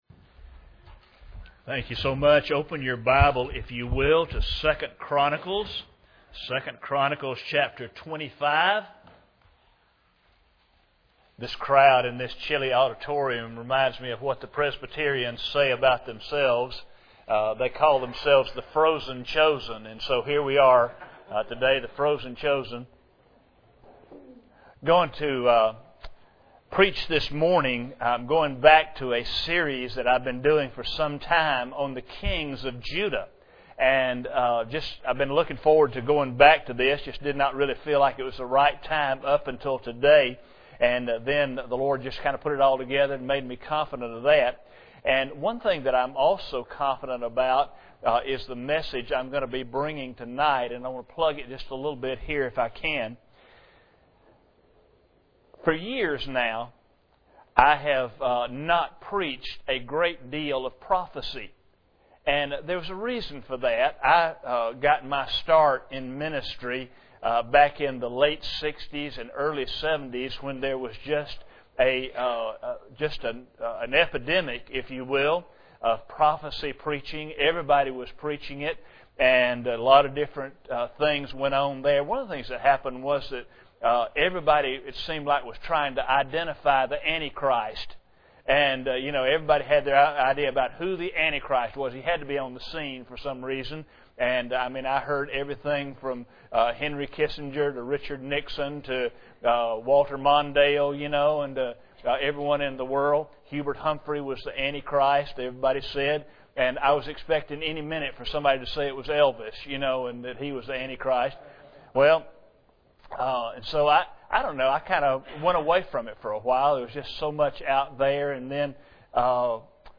During the sermon
Service Type: Sunday Morning